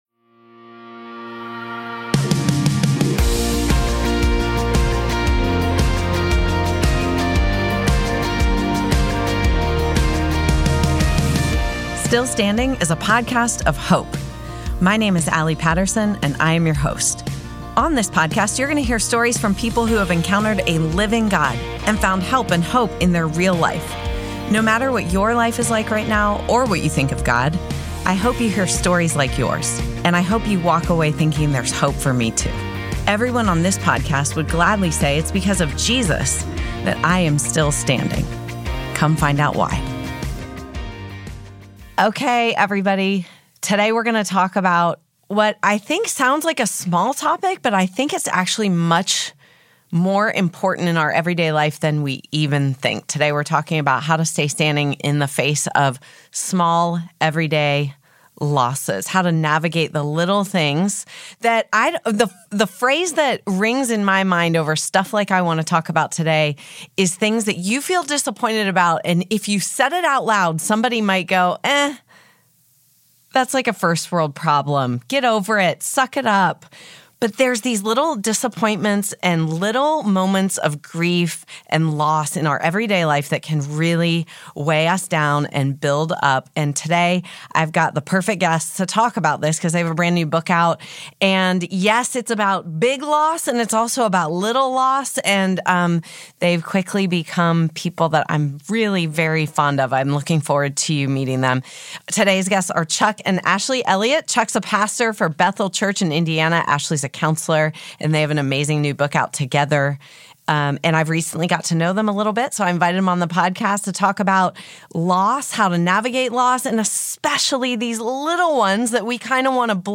We’re not meant to dwell there, God wants us to move. This conversation will encourage you to experience hope in the midst of everyday loss.